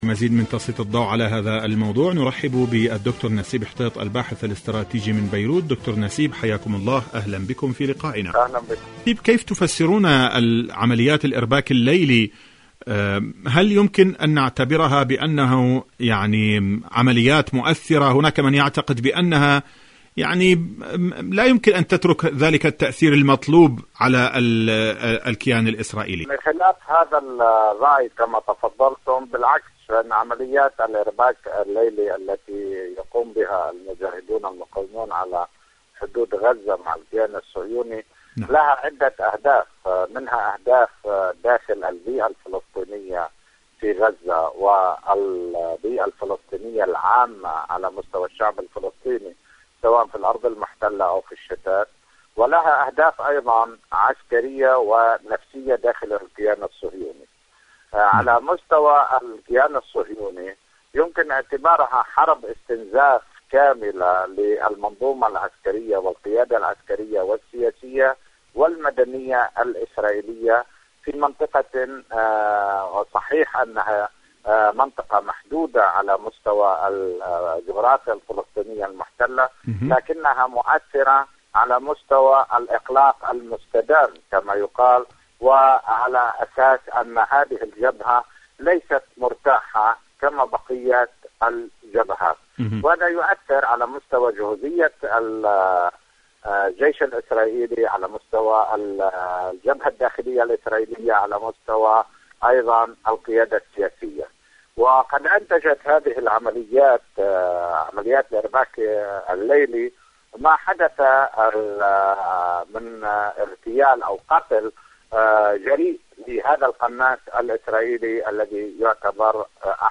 مقابلات برنامج ارض المقاومة المقاومة مقابلات إذاعية محور المقاومة عمليات الإرباك الليلي سيف ذو حدين الاحتلال الصهيوني شاركوا هذا الخبر مع أصدقائكم ذات صلة دور العلاج الطبيعي بعد العمليات الجراحية..